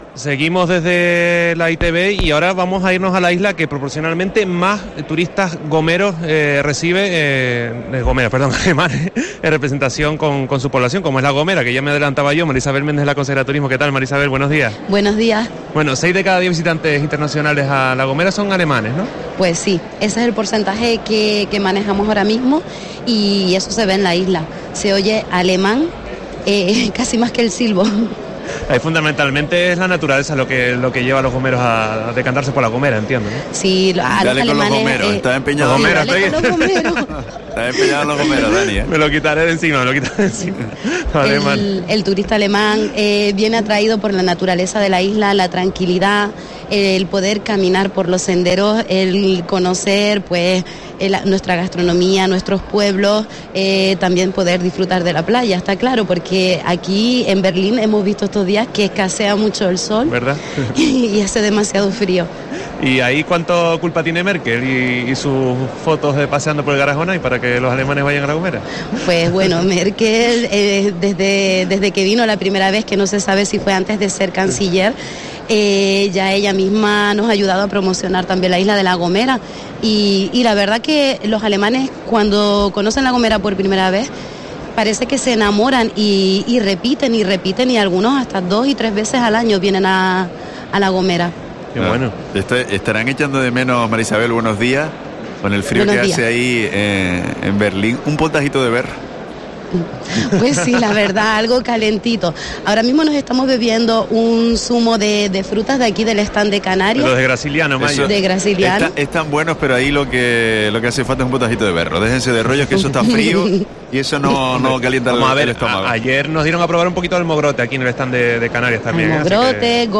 Entrevista a María Isabel Méndez, consejera de Turismo del Cabildo de La Gomera